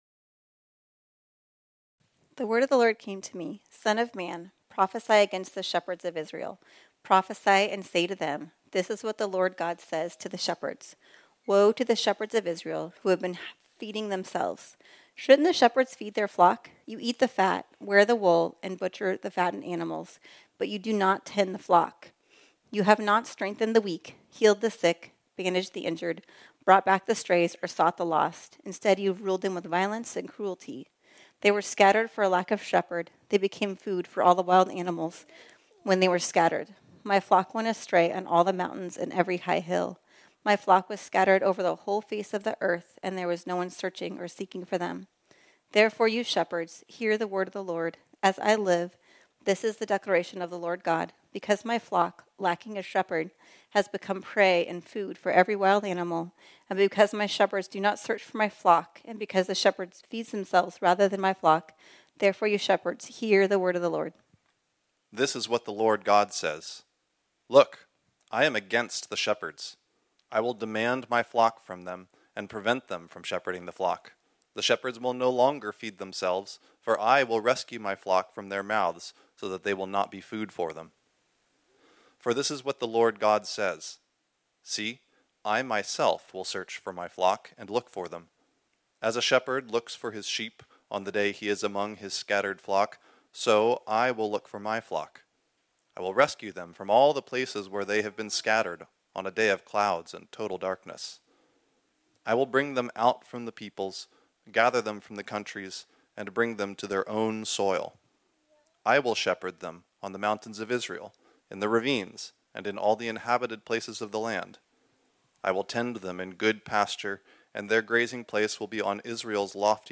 This sermon was originally preached on Sunday, December 3, 2023.